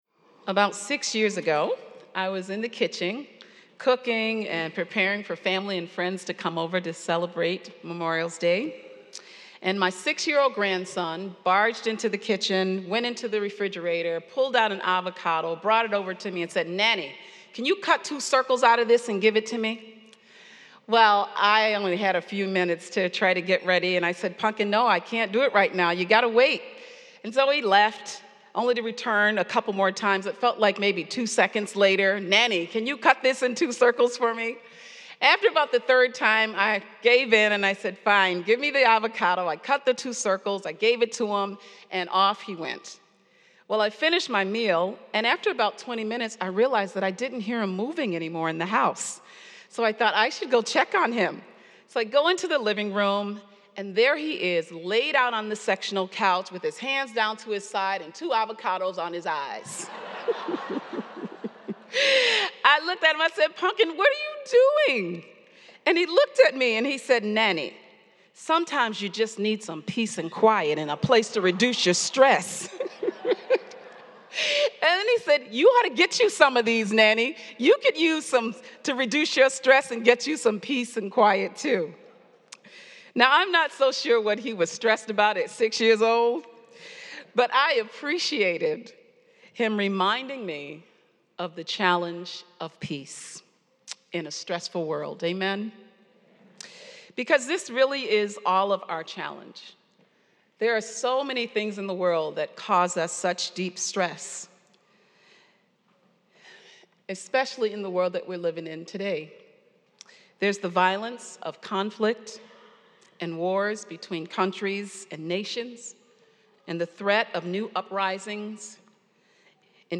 preaches on the challenge of peace